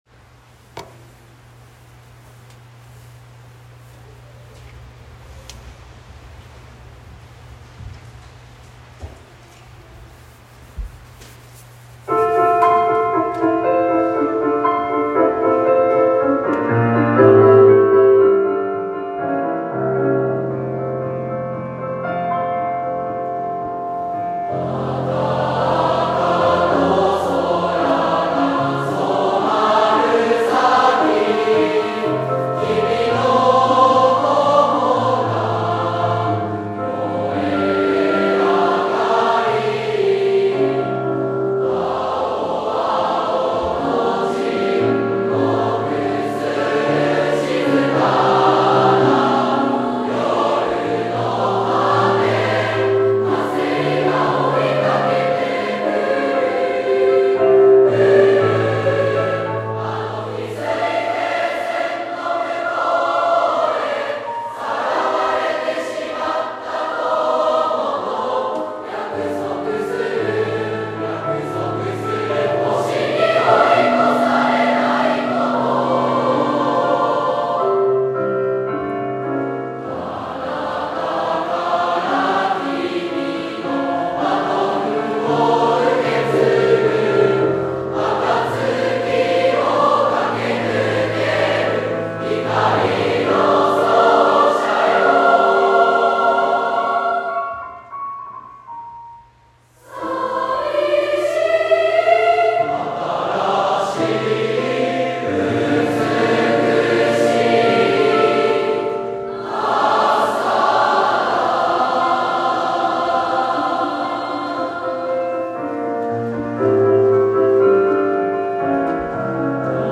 令和６年度の全校追求曲は、信長高富／混声合唱曲「光の走者よ」（作詞：和合亮一）です。
学校見学会 全校音楽集会（４月27日）の合唱音源（m4aファイル）